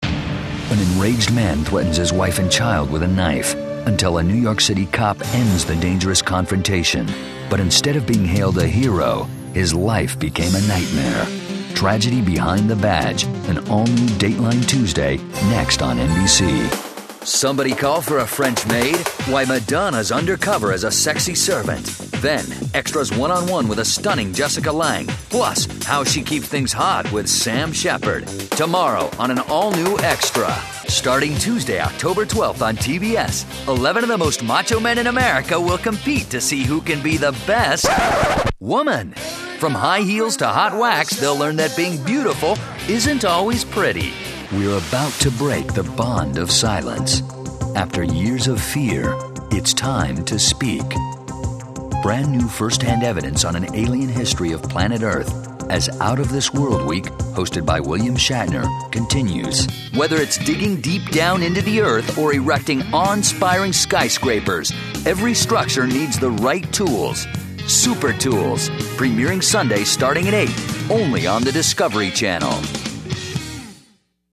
Top Bilingual Voice Over Talent in English and Spanish that has recorded hundreds of commercials, promos, narrations, corporate videos, and other project for Fortune 500 companies around the globe.
Sprechprobe: Industrie (Muttersprache):